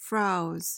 PRONUNCIATION: (frowz) MEANING: verb tr., intr.: To be or to make untidy, tangled, or ruffled. noun: A wig of frizzed hair.